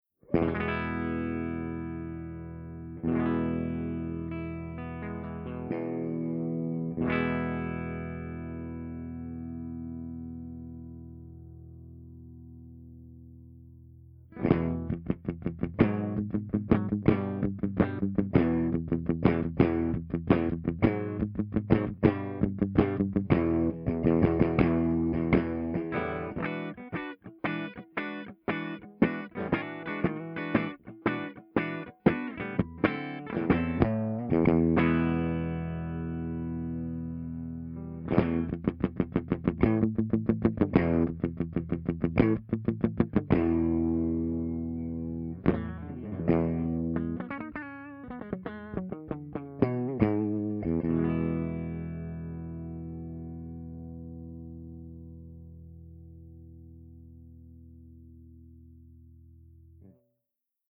057_FENDERTWIN_WARM_HB
057_FENDERTWIN_WARM_HB.mp3